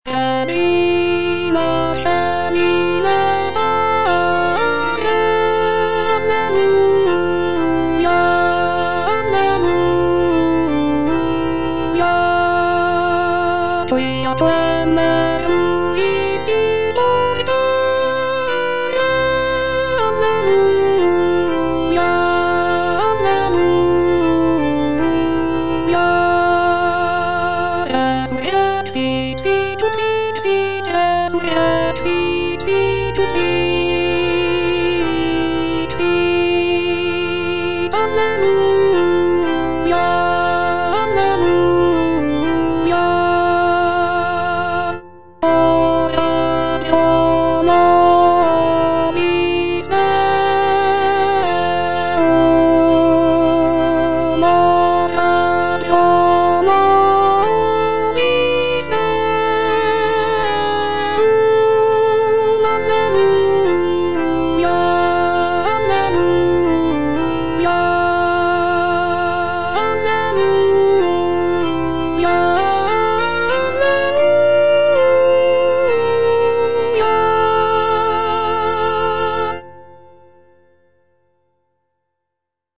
Répétition SATB4 par voix
Alto